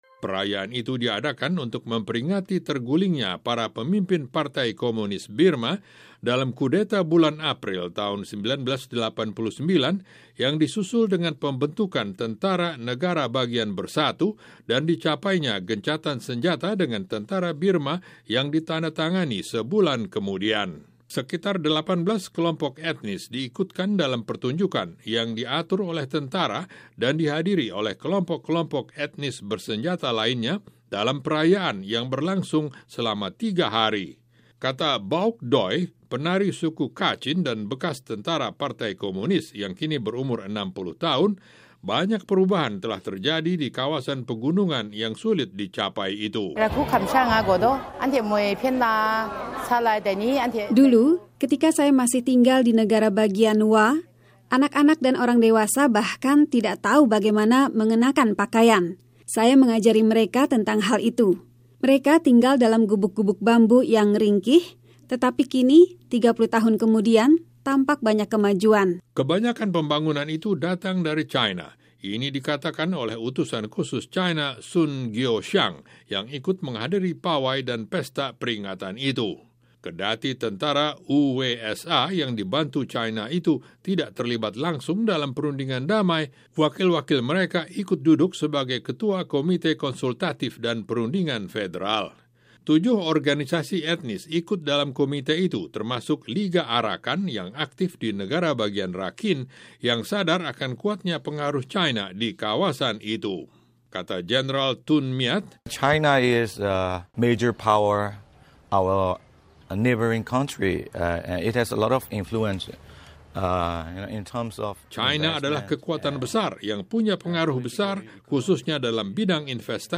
Suara ledakan keras terdengar dan asap mengepul diatas stadion nasional di ibukota negara bagian Shan di Myanmar. Itu adalah bagian dari peringatan yang diadakan oleh Tentara Negara Bagian Bersatu atau UWSA bulan lalu, untuk merayakan pemerintahan otonomi mereka.